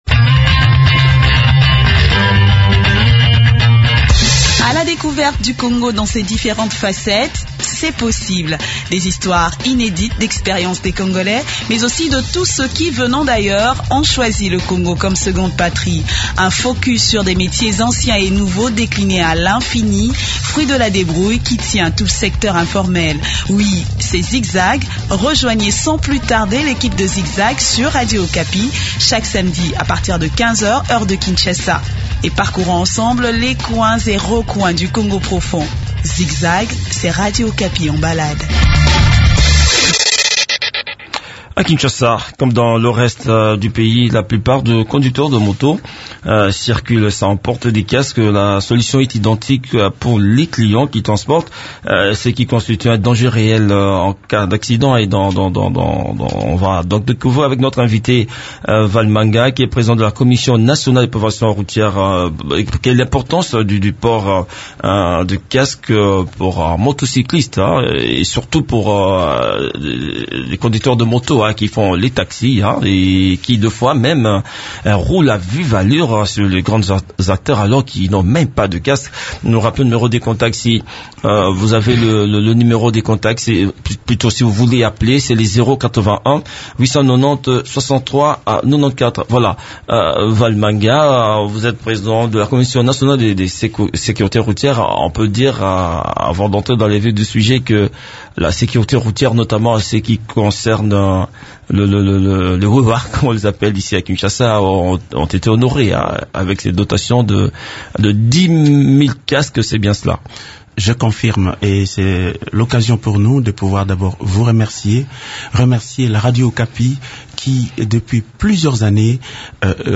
discute de ce sujet avec Vale Manga, président de la Commission nationale de prévention routière(CNPR).